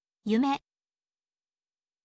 yume